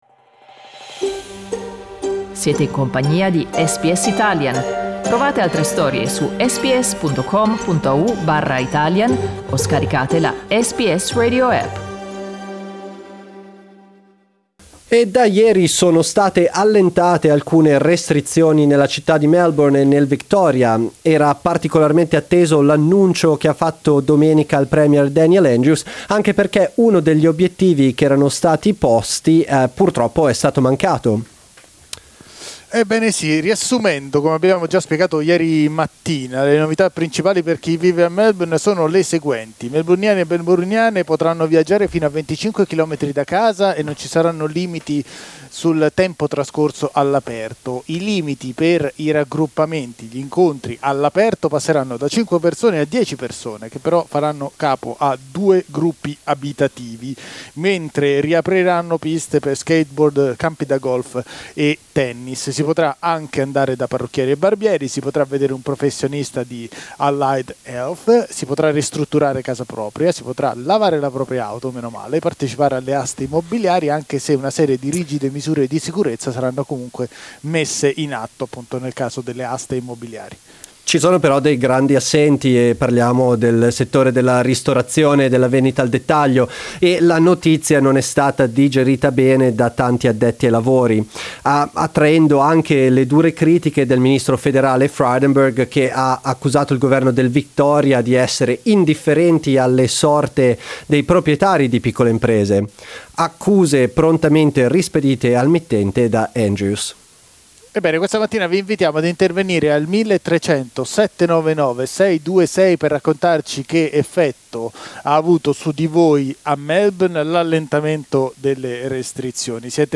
Lo abbiamo chiesto agli ascoltatori di SBS Italian.